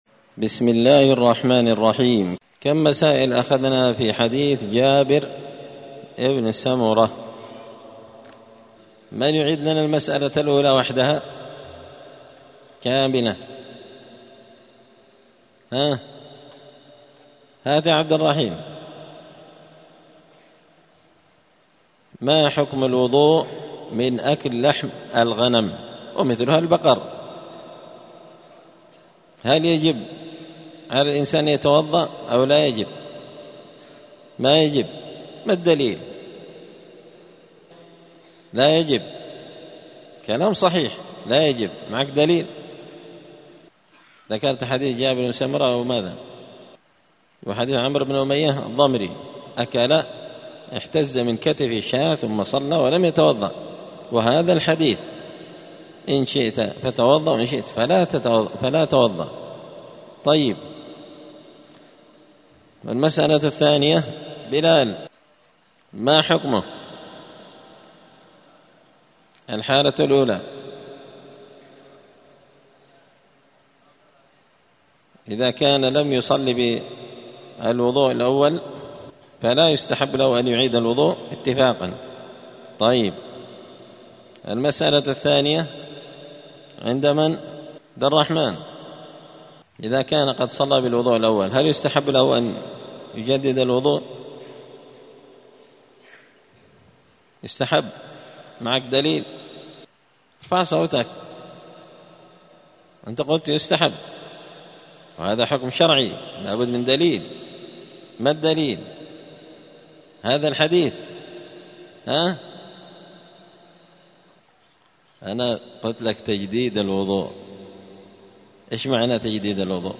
دار الحديث السلفية بمسجد الفرقان قشن المهرة اليمن
*الدرس الرابع والخمسون [54] {باب ما ينقض الوضوء حكم الوضوء من أكل لحم الإبل}*